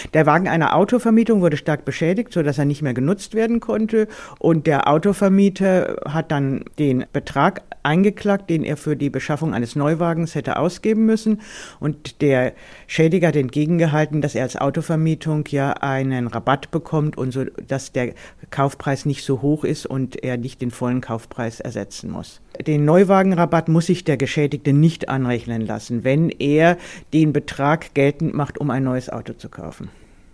O-Ton: Neuwagenrabatt verringert Schadensersatz nicht – Vorabs Medienproduktion